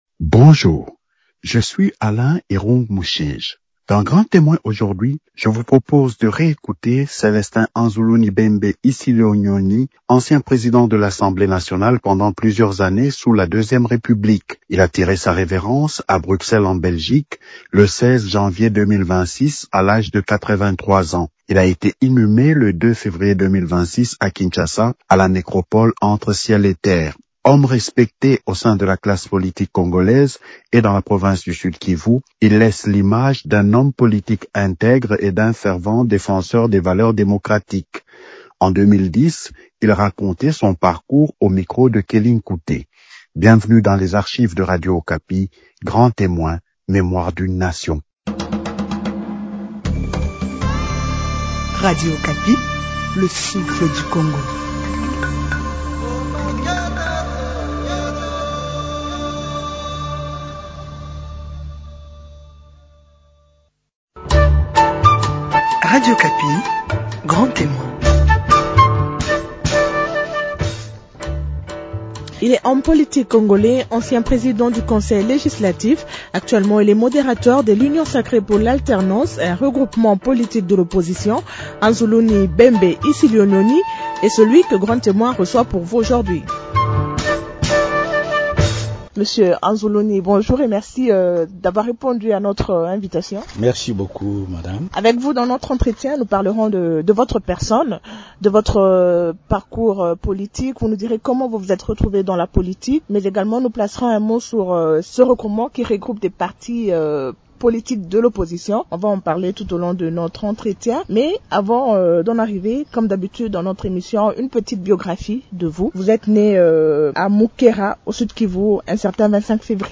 Bienvenu dans les archives de Radio Okapi, Grand Témoin Mémoire d’une nation.